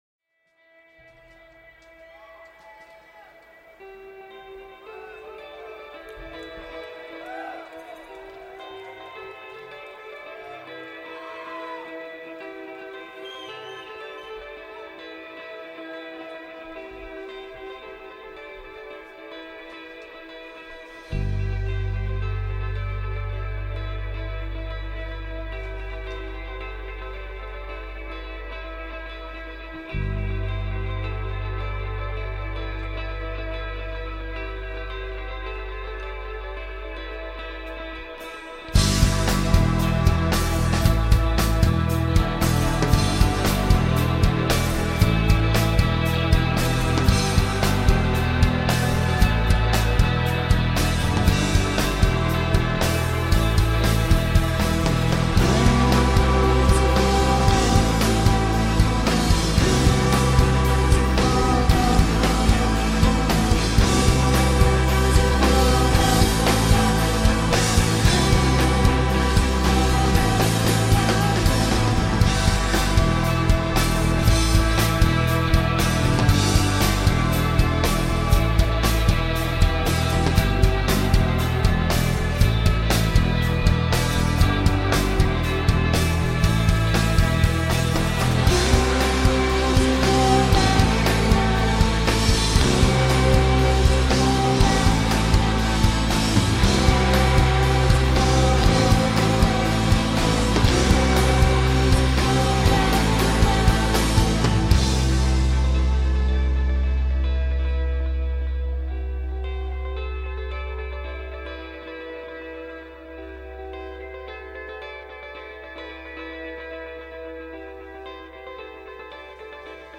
Shoegaze